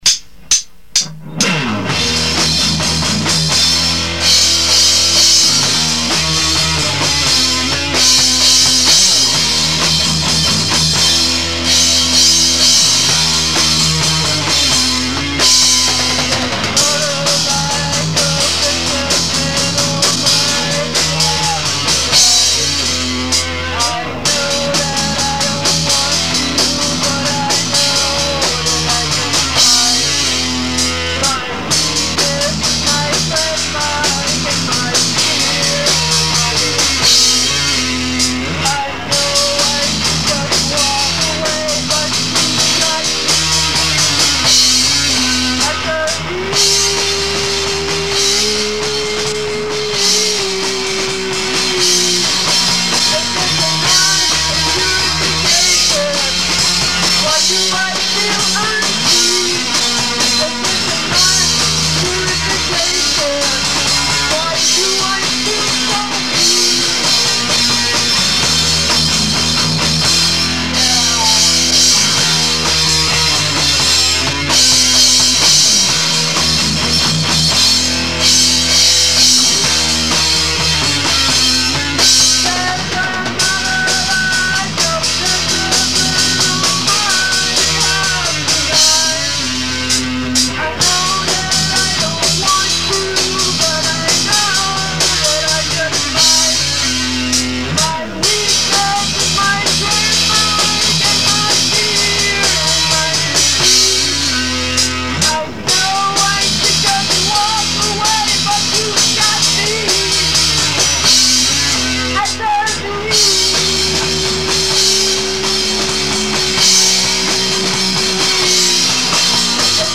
THE GRUNGIEST I EVER GOT
This song is the only decent recording I can find & it's from a practice in my living room while we were still working out the arrangement.